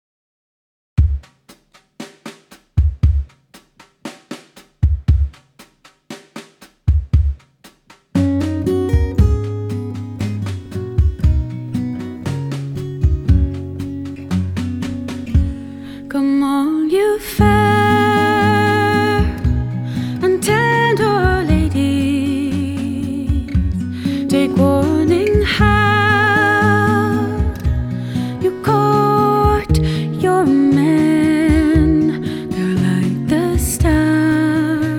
Жанр: Рок / Фолк